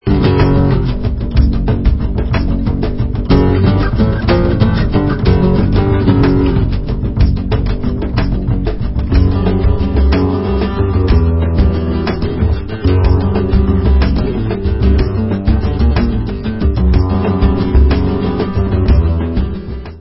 sledovat novinky v oddělení Pop/Instrumental